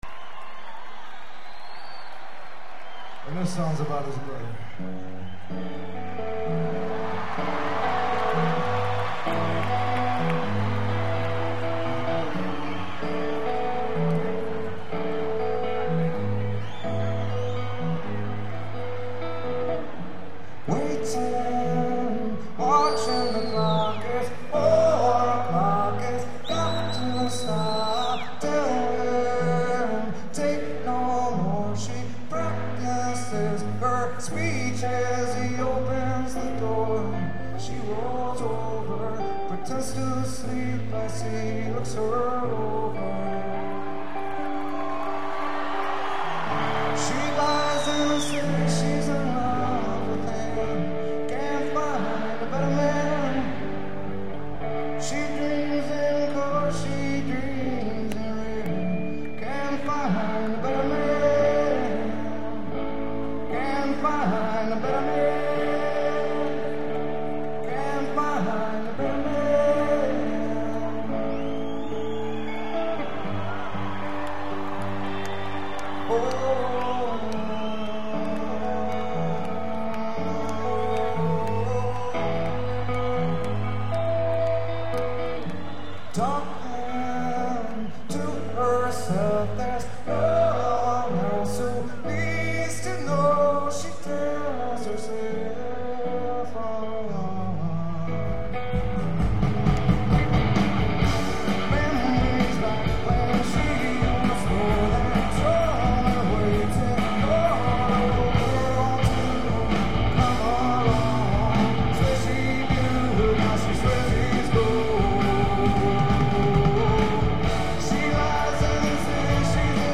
as performed at Madison Square Garden 9/11/98.